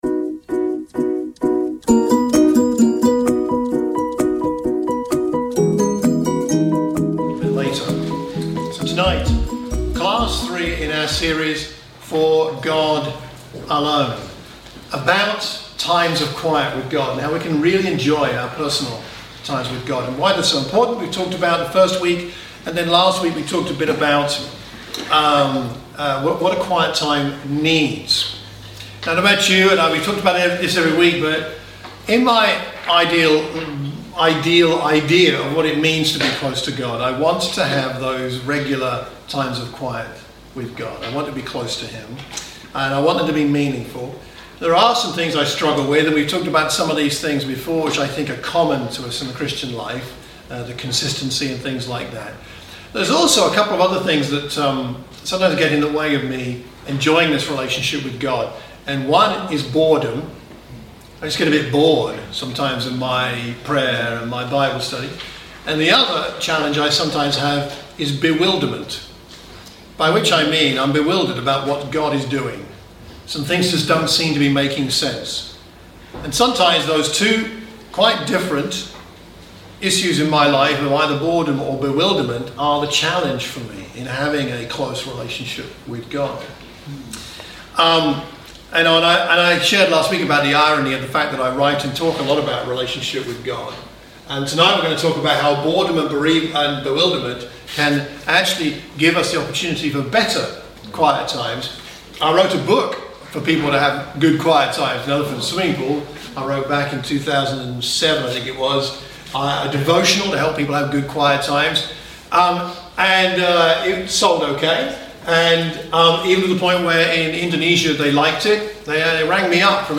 This class series is designed to refresh our desire for a daily focused time with God - something that is often called a "quiet time".